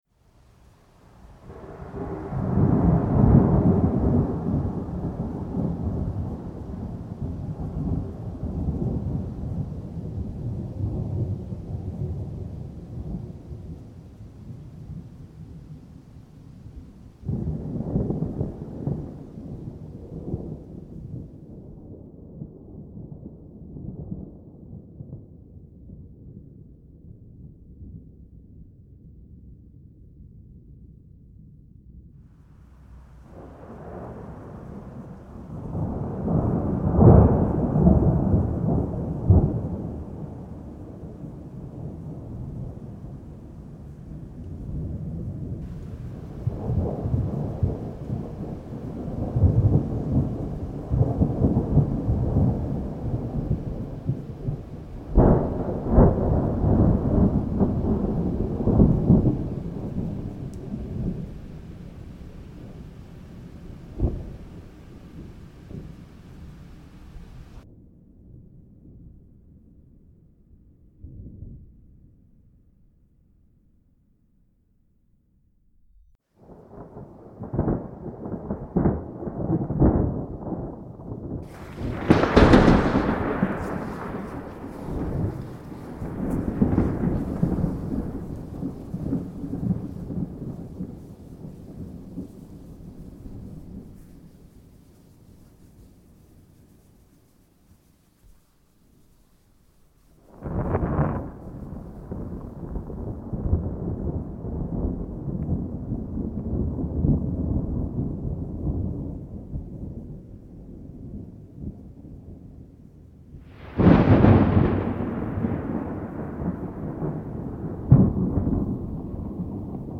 Index of /Relaxing/Nature/Rain/